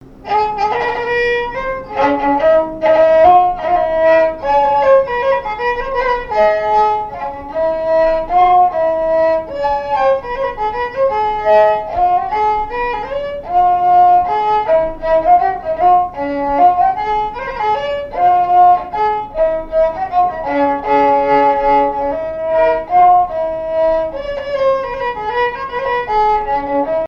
Chants brefs - A danser
danse : mazurka
Répertoire de marches de noce et de danse
Pièce musicale inédite